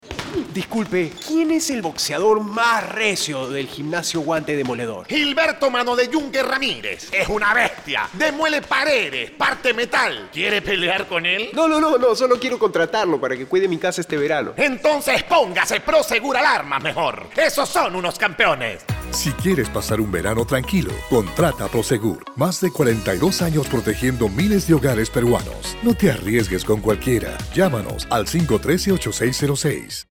La compañía Prosegur Alarmas ha puesto al aire dos spots radiales que hacen énfasis en la prevención para poder evitar un siniestro.
La idea de la presente campaña nace desde la necesidad de comunicar el servicio de la Alarma Prosegur Smart, para ello se utilizó un tono comunicacional empático con una comunicación disruptiva y amena, sin perder la seriedad de la marca.